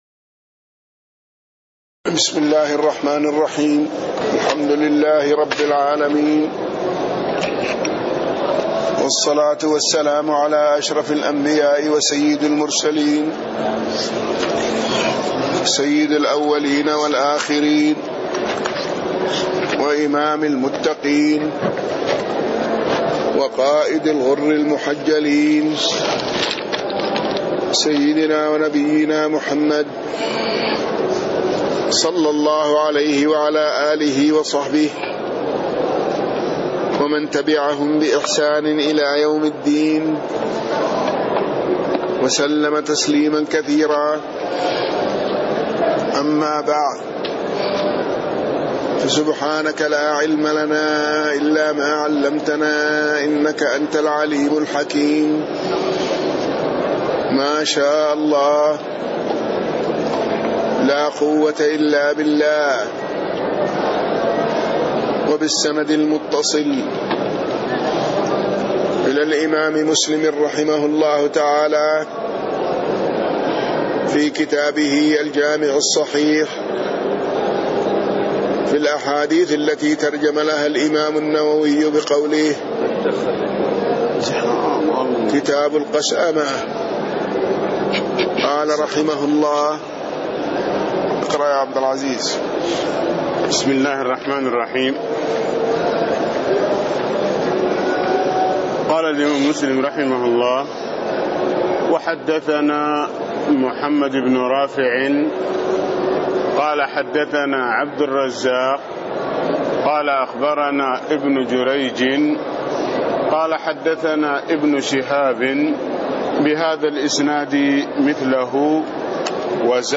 تاريخ النشر ٢٩ جمادى الأولى ١٤٣٥ هـ المكان: المسجد النبوي الشيخ